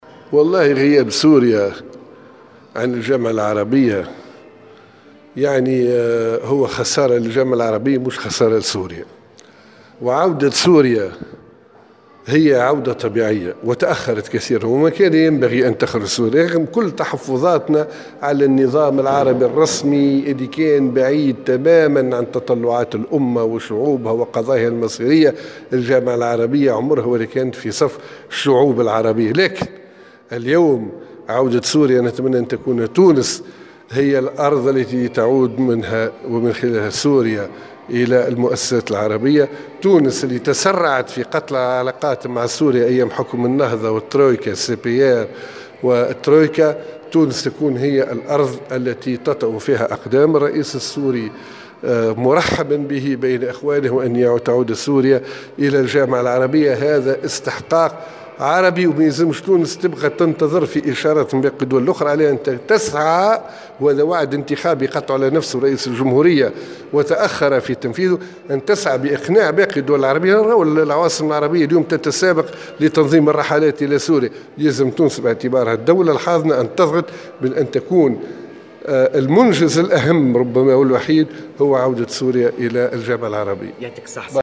وأضاف في تصريح لموفد "الجوهرة أف ام" على هامش انعقاد اللجنة المركزية لحزبه بسوسة، أن حكومة الترويكا ارتكبت خطأ جسيما بقطعها للعلاقات الديبلوماسية مع سوريا والآن بعض الدول العربية تهرول لإعادة علاقاتها معها.